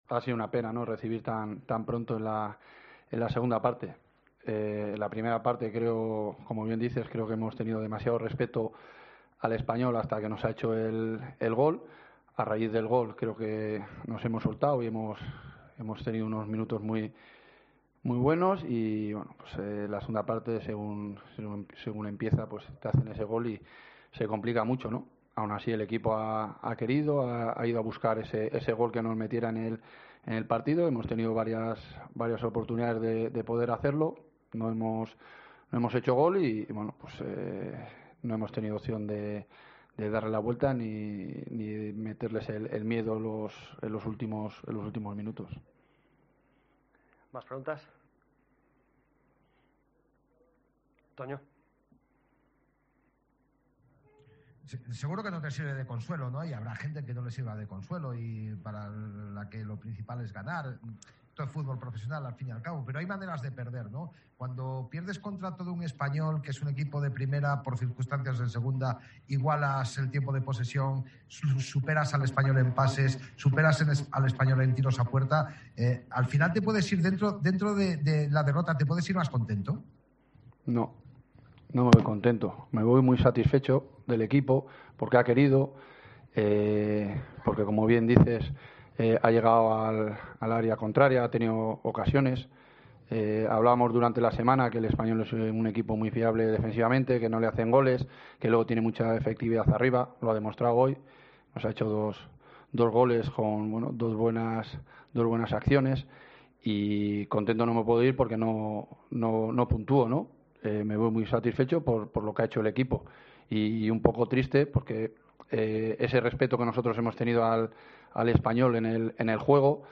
AUDIO: Escucha aquí el postpartido con declaraciones de Jon Pérez Bolo, entrenador de la Deportiva, y Vicente Moreno, míster del Español